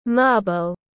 Viên bi tiếng anh gọi là marble, phiên âm tiếng anh đọc là /ˈmɑː.bəl/